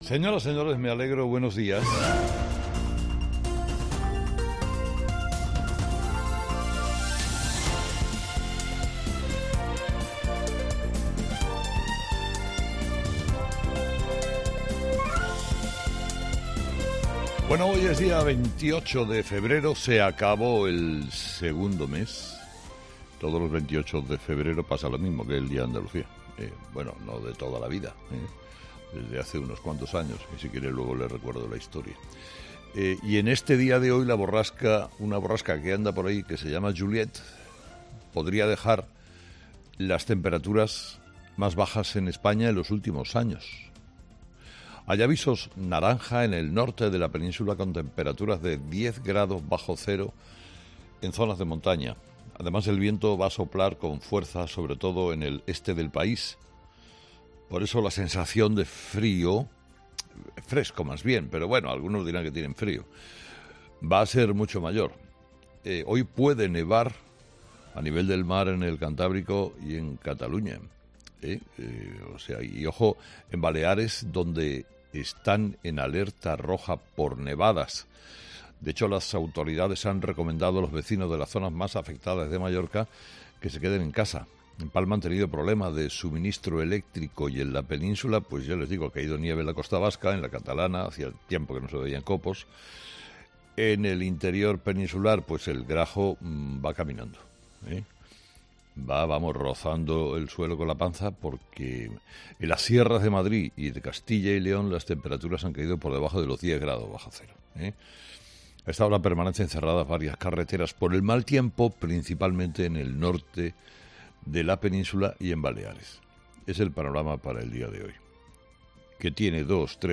Carlos Herrera, director y presentador de 'Herrera en COPE', comienza el programa de este martes analizando las principales claves de la jornada, que pasan, entre otros asuntos, por el 'caso Mediador' que perturba al PSOE en esta carrera electoral hacia las elecciones autonómicas y municipales.